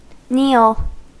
Ääntäminen
Ääntäminen : IPA : /niːl/ US : IPA : [niːl] Haettu sana löytyi näillä lähdekielillä: englanti Käännös Verbit 1. polvistua Määritelmät Verbi (intransitive) To rest on one's bent knees , sometimes only one; to move to such a position.